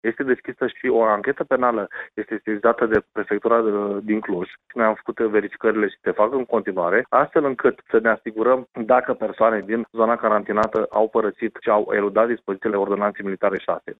Prefectul de Suceava, Alexandru Moldovan, a declarat pentru postul nostru de radio, că în acest caz a fost deschis un dosar penal şi că în prezent se fac verificări încrucişate pentru a se stabili dacă persoanele respective au părăsit zona carantinată.